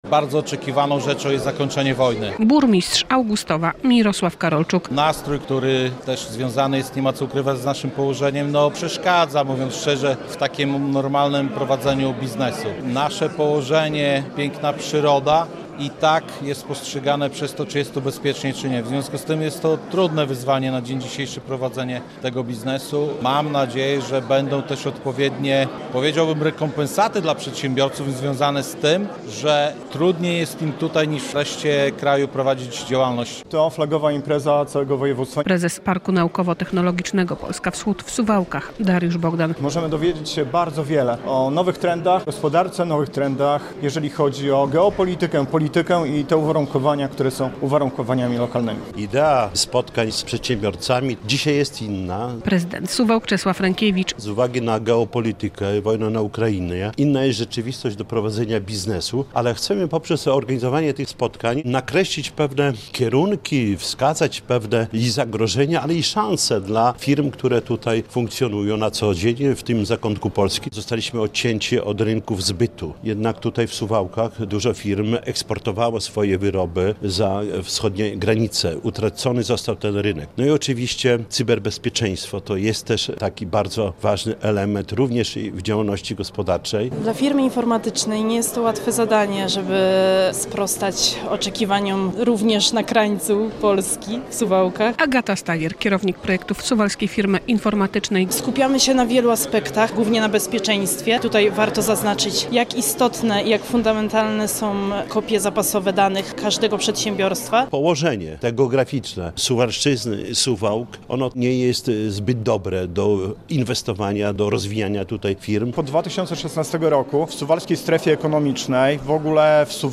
Forum Biznesowe Pogranicza - relacja